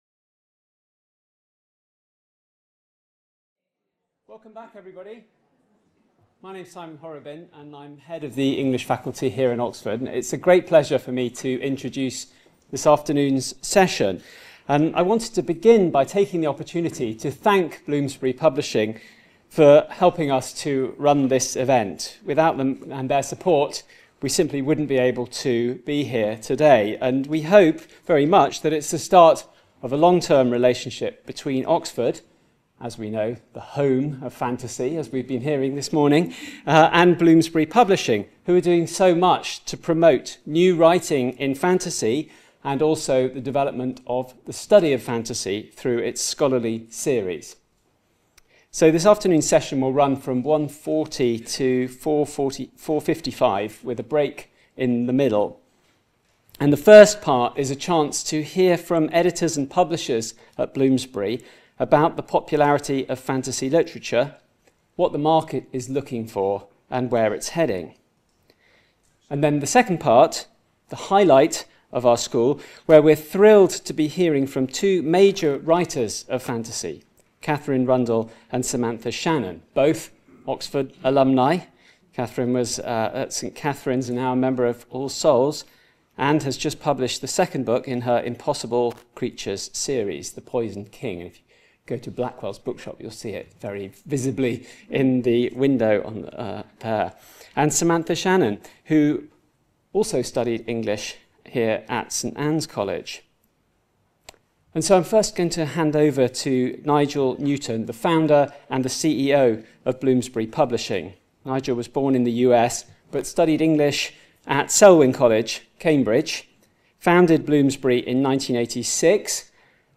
A lengthy discussion by commissioning editors on what they are looking for in new fantasy fiction and the current market. Part of the Bloomsbury-Oxford Summer School (23rd-25th September 2025) held at Exeter College.